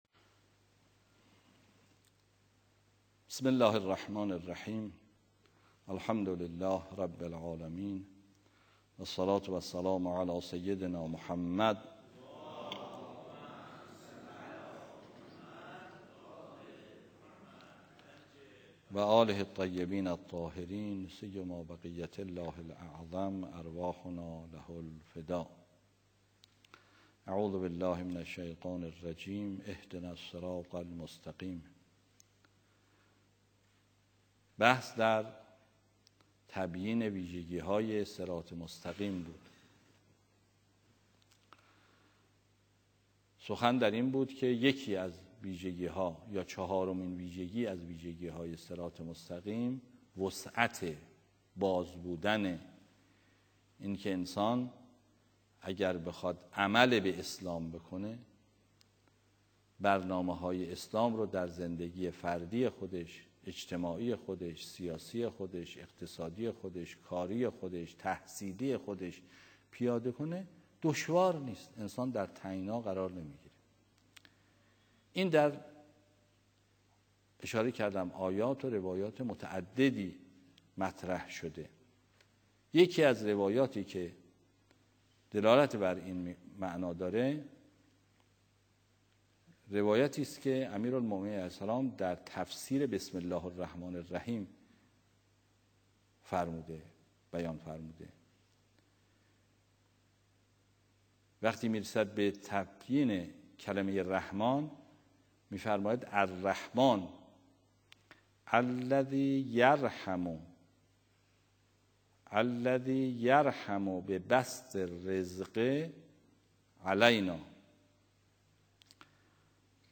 آیت‌الله ری‌شهری در جلسه تفسیر قرآن: